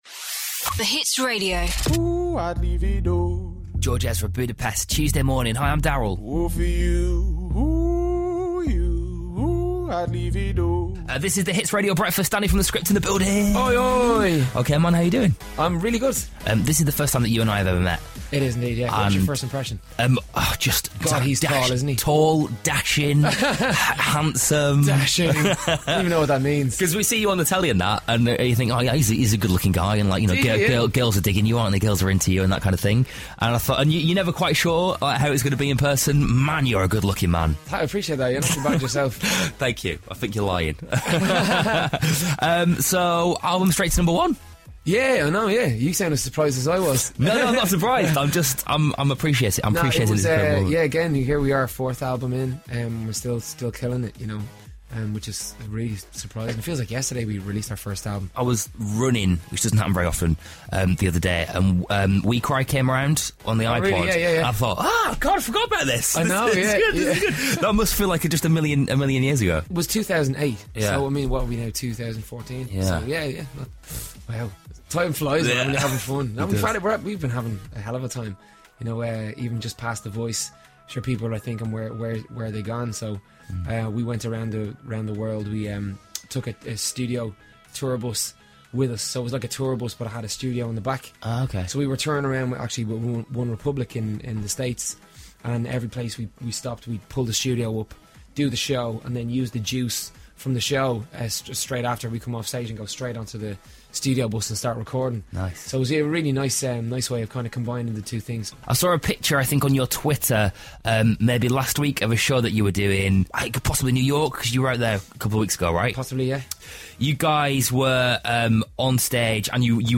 Danny popped in to man the phones for 'Danny Direct' and answer fan dilemmas. Listen as he soothes us all with his dulcet Irish tones.